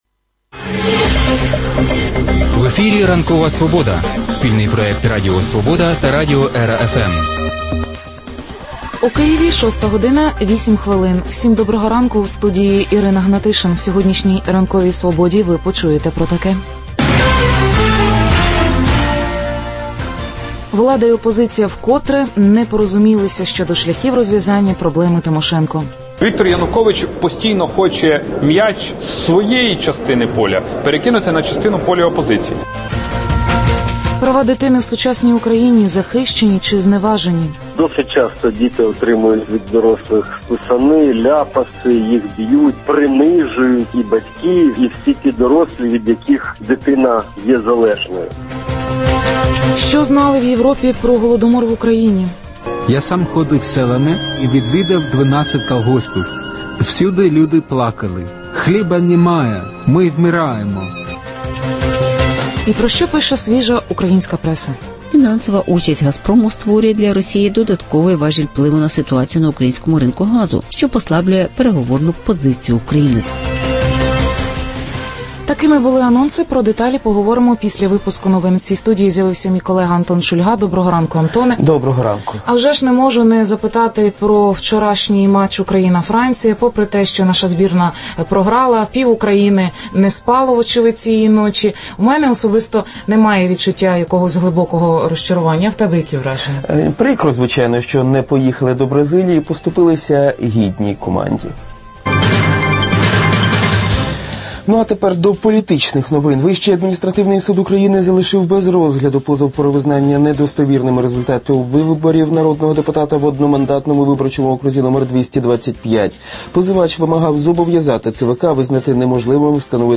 Ексклюзивне інтерв’ю дитячого омбудсмана Юрія Павленка. Що дасть Україні судова реформа за європейським зразком? У Криму знову підпалюють мечеті – суспільство сприймає хуліганство як міжрелігійні провокації?